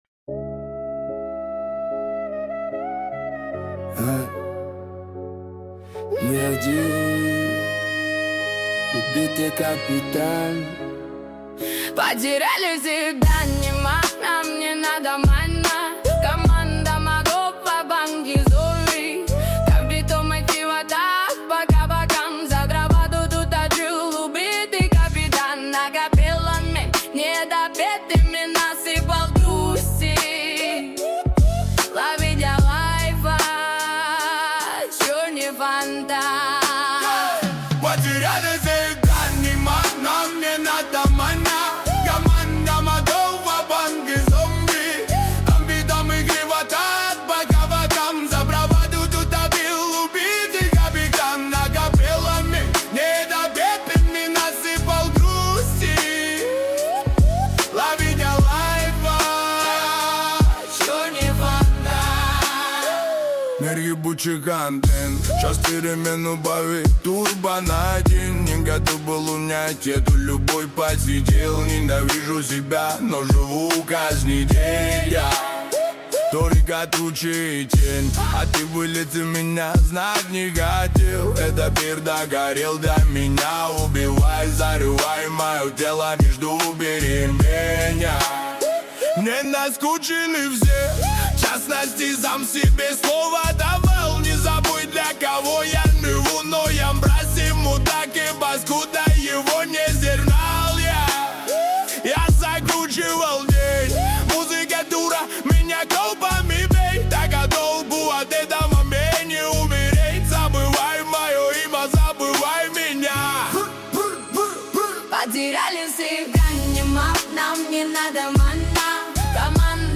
Соул версия